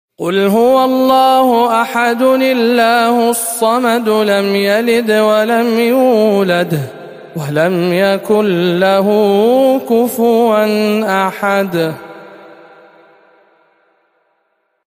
سورة الإخلاص بجامع أم الخير بجدة - رمضان 1439 هـ